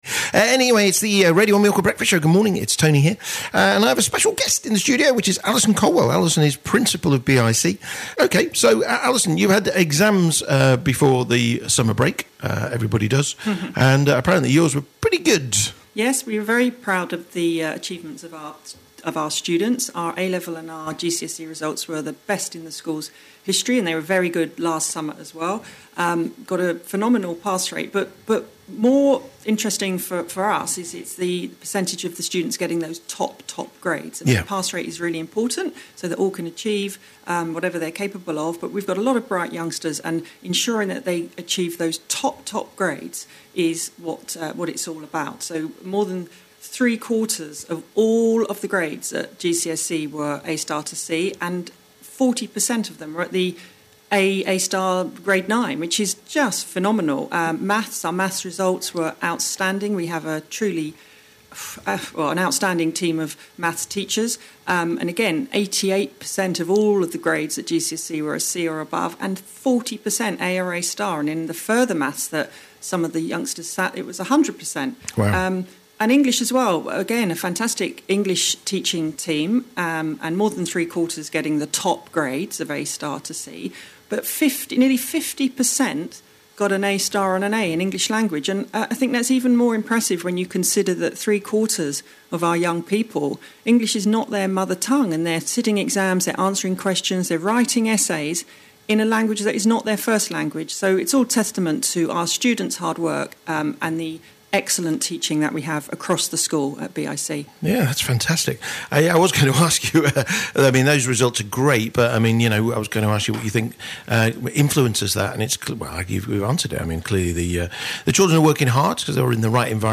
live on Radio 1 Mallorca